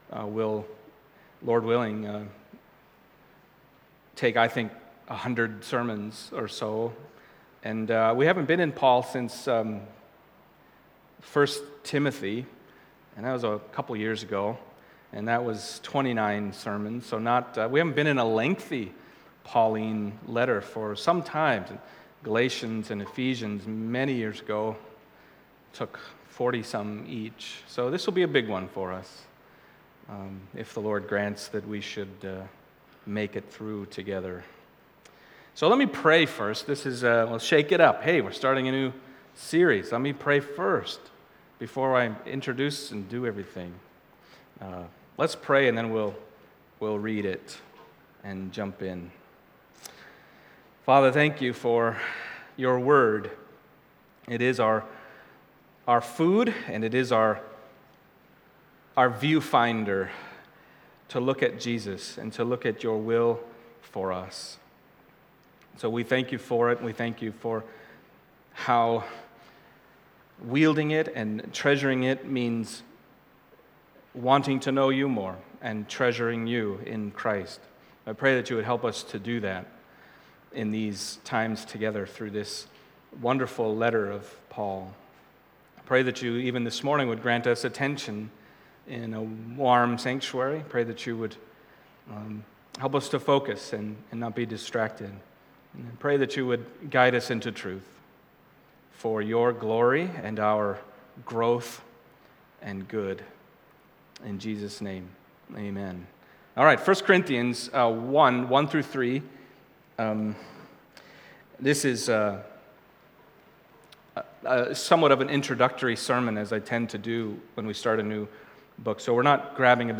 1 Corinthians 1:1-3 Service Type: Sunday Morning 1 Corinthians 1:1-3 « Giving